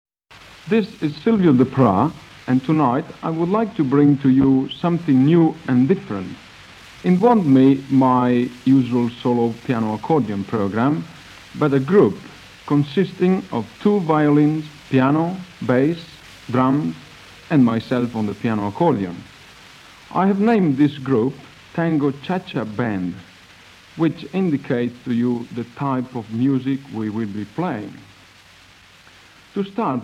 accordion
tango